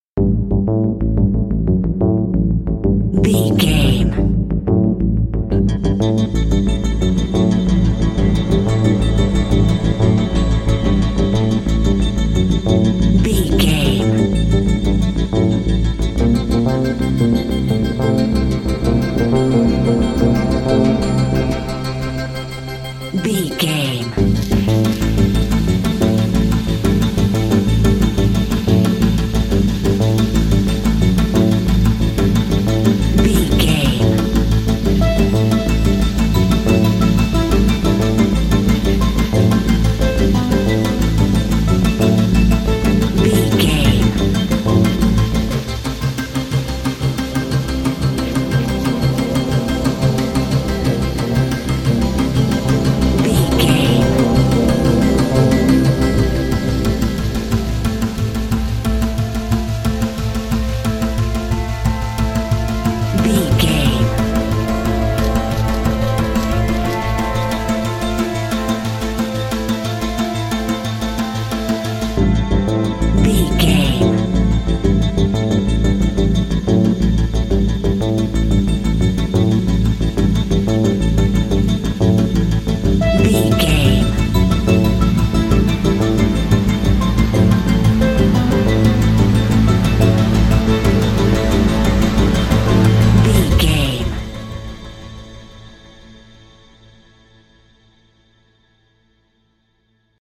Aeolian/Minor
synthesiser
drum machine
ominous
dark
suspense
haunting
creepy